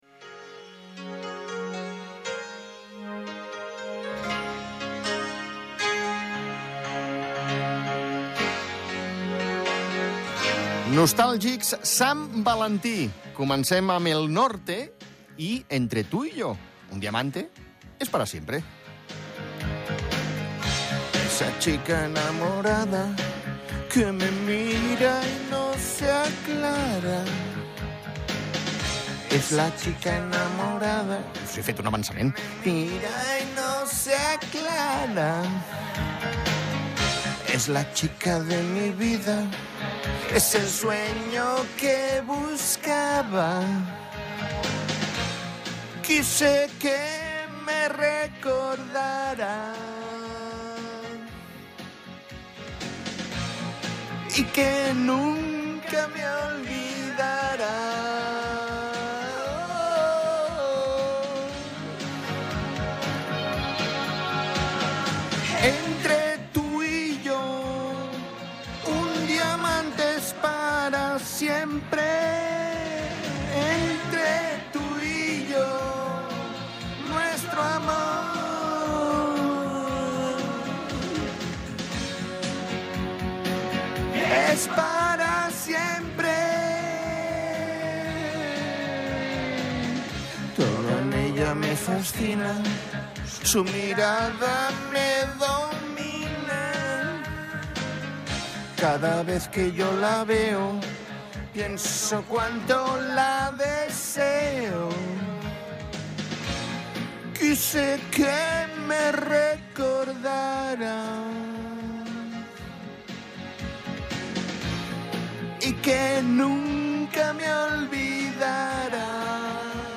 Per aquesta raó, amb cançons que ens heu fet arribar, escoltem, cantem i interpretem temes d’amor i de desamor!!